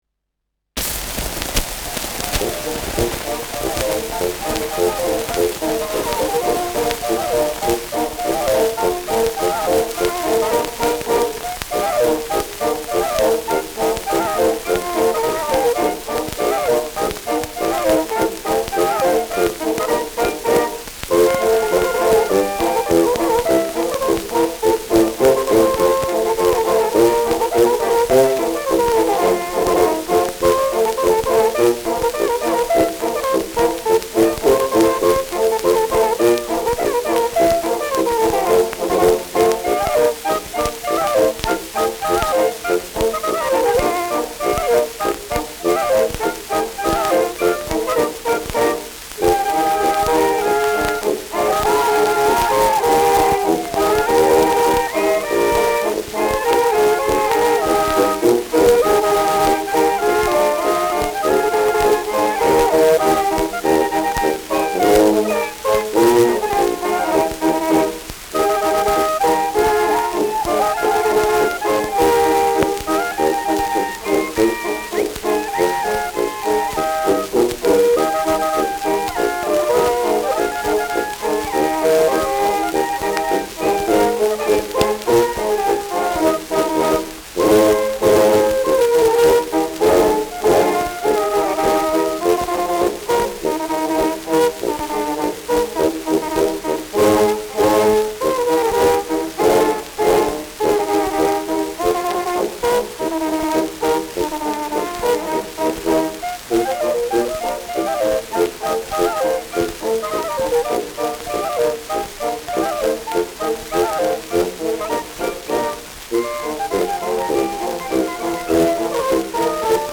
Schellackplatte
starkes Rauschen : abgespielt : leichtes Knacken durchgehend : abgespielt : leichtes Leiern
Mit Pfiffen.
[Nürnberg] (Aufnahmeort)